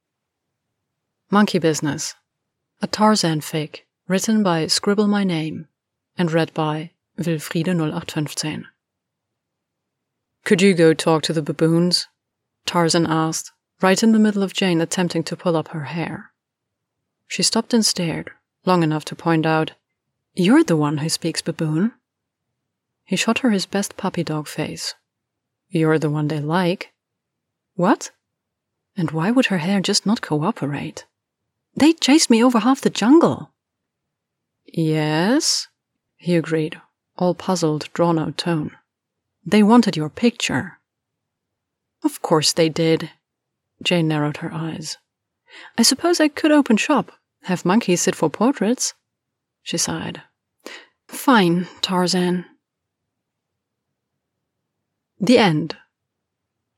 no effects